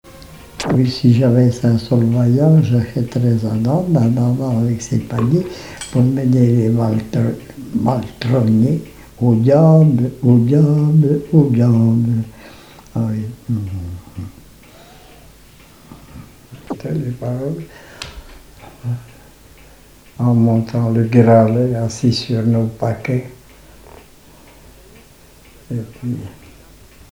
Couplets à danser
chansons et témoignages parlés
Pièce musicale inédite